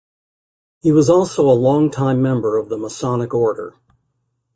Pronunciado como (IPA)
/məˈsɑnɪk/